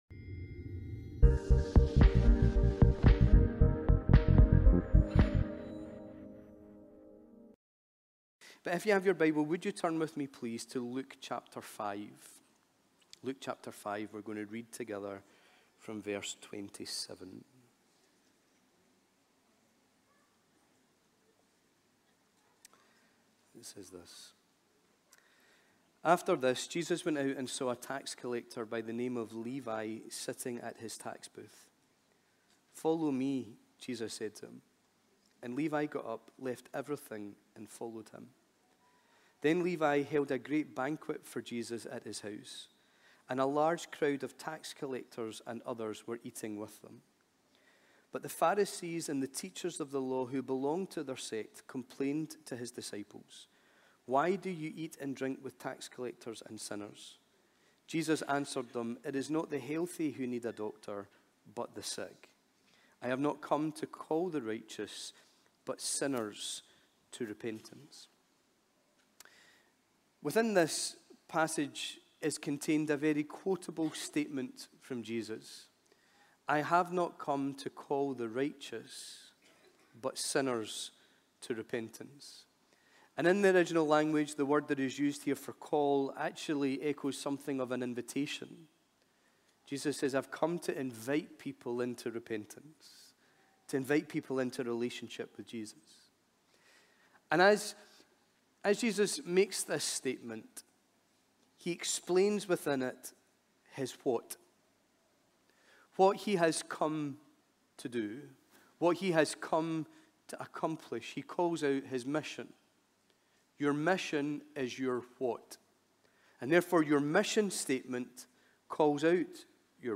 Teaching and Preaching from Glasgow Elim Church.